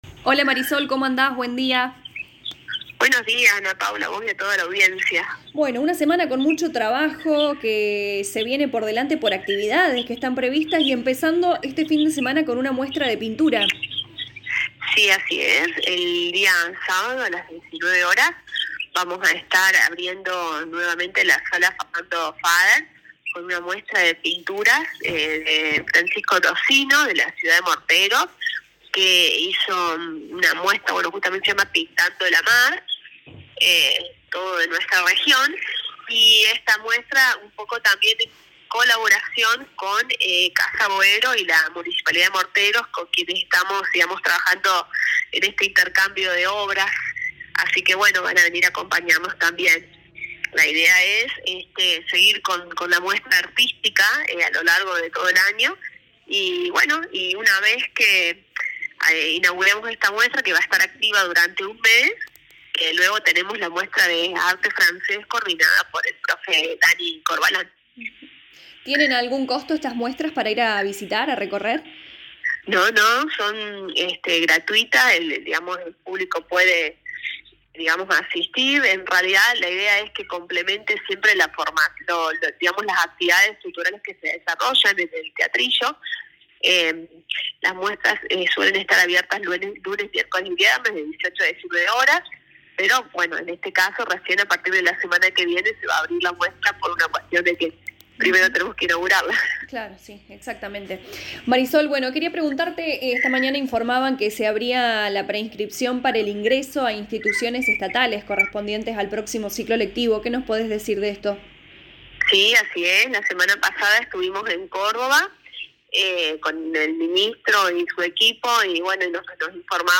La secretaria de Educación y Cultura Lic. Marisol Núñez dialogó con LA RADIO 102.9 Fm y brindó detalles organizativos del Congreso de Educación que se realizará en nuestra ciudad el 17 y 18 de septiembre.